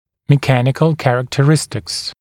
[mɪ’kænɪkl ˌkærəktə’rɪstɪks][ми’кэникл ˌкэрэктэ’ристикс]механические характеристики